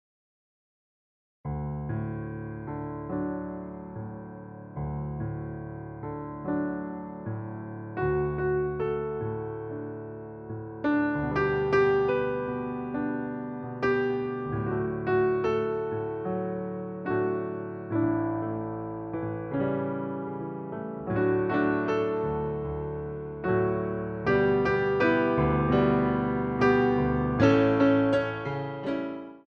Battement Fondu